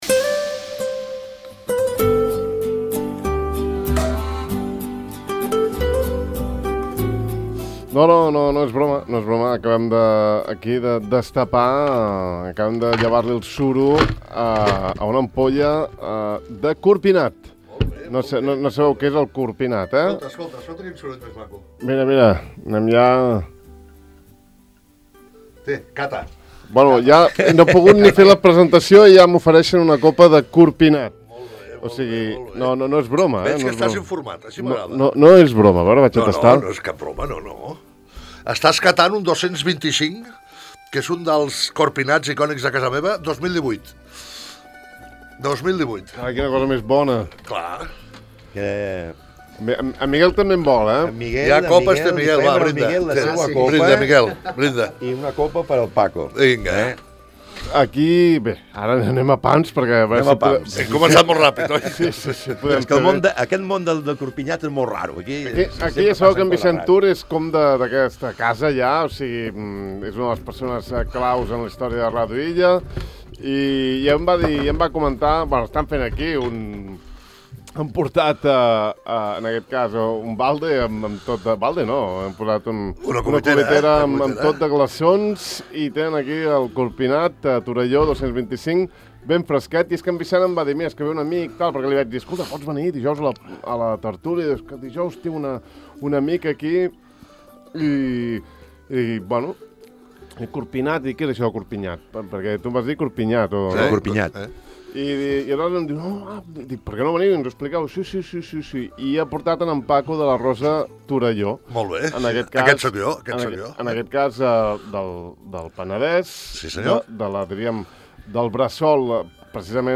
Amb ells dos hem xerrat una bona estona sobre el Corpinnat… i l’hem tastat en directe: